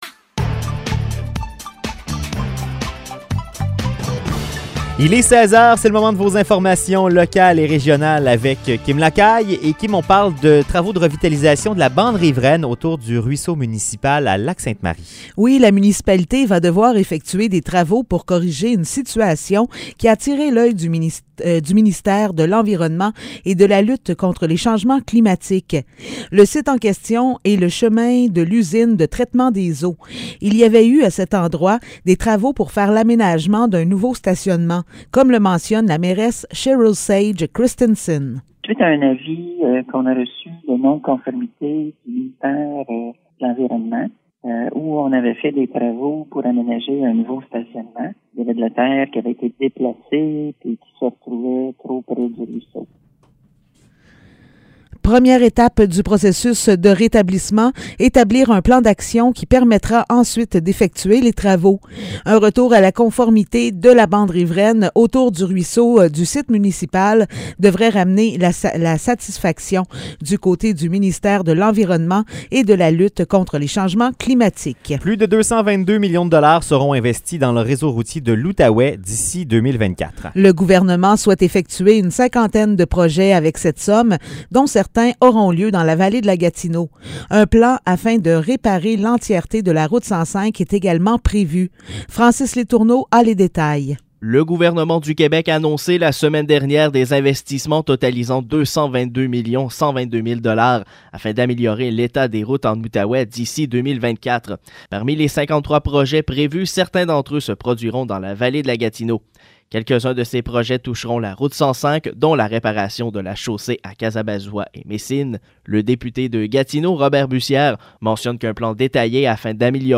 Nouvelles locales - 21 mars 2022 - 16 h